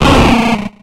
Cri de Magby dans Pokémon X et Y.